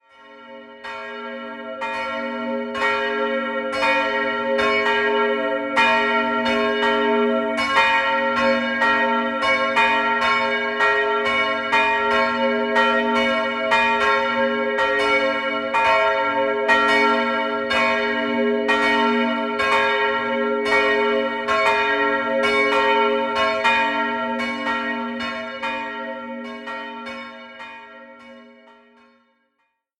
Glockenklänge.
Bronzegeläute (ca. 14. Jahrhundert bis heute)
Kirchfembach: Mittelalterliches Glockenterzett (13.-15. Jhdt.)